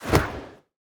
throw-projectile-2.ogg